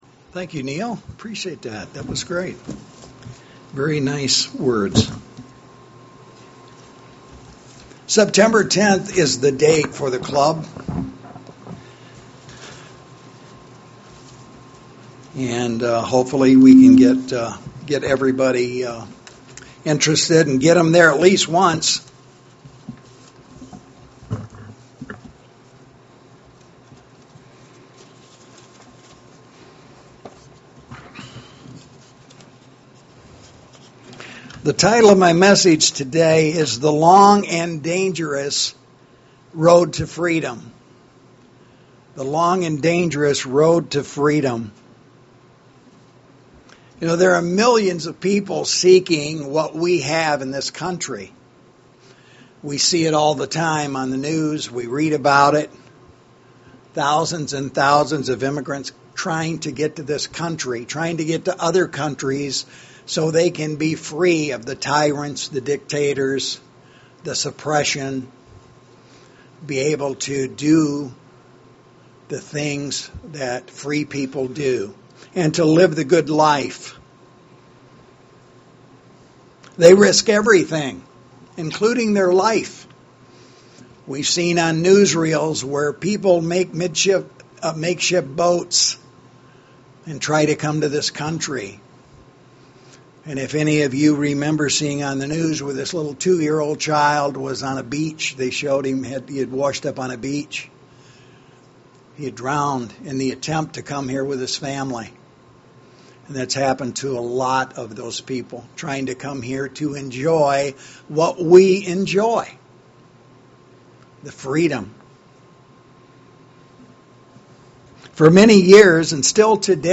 Sermons
Given in Lansing, MI